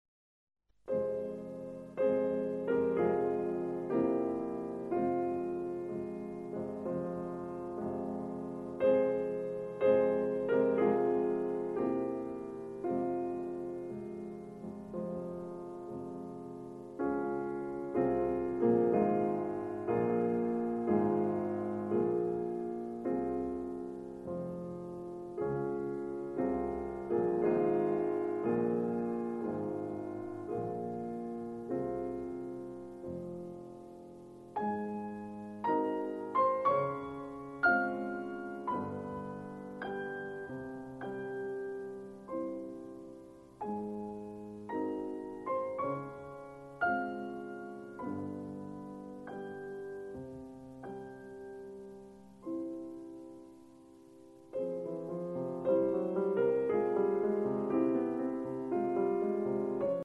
Piano Music